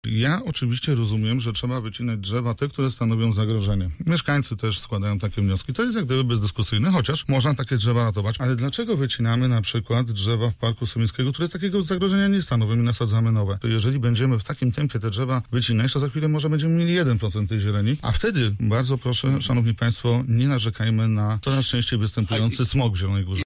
Zielona Góra, to było kiedyś miasto – ogród, przypomniał na antenie Radia Zielona Góra radny klubu PiS Jacek Budziński i zaapelował do władz o powrót do tej tradycji.